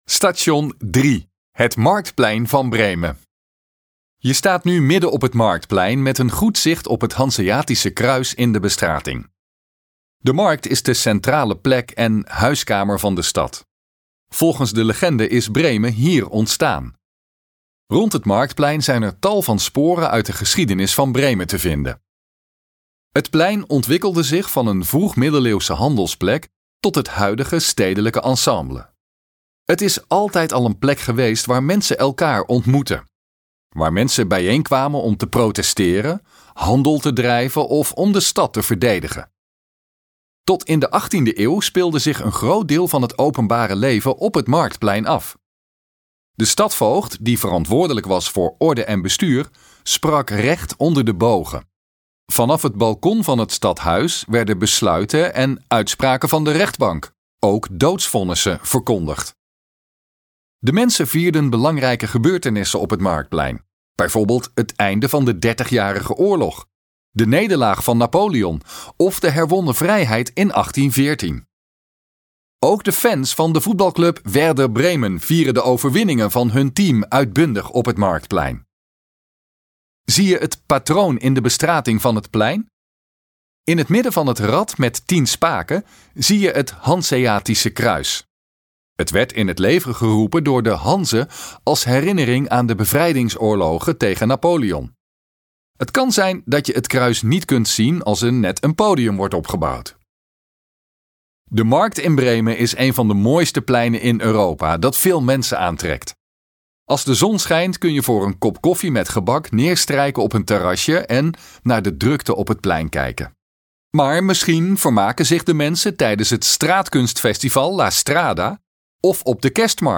Gratis audiogids: Een wandeling door de historische binnenstad van Bremen